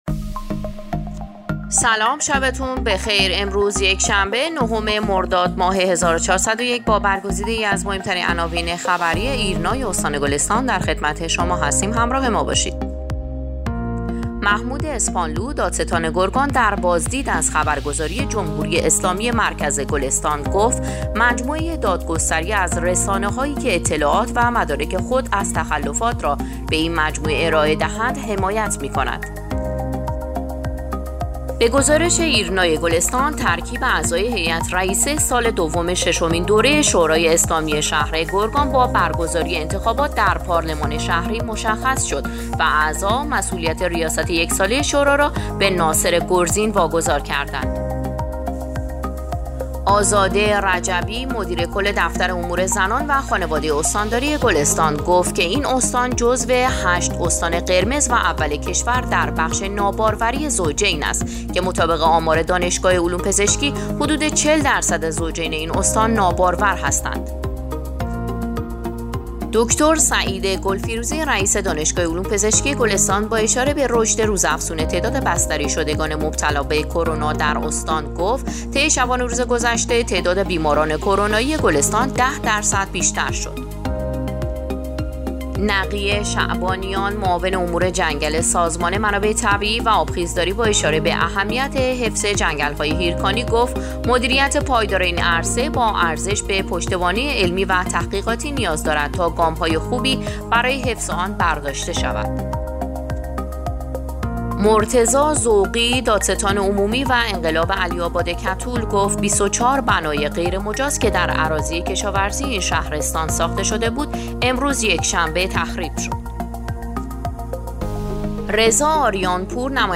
صوت/ اخبار شبانگاهی ۹ مردادماه ایرنا گلستان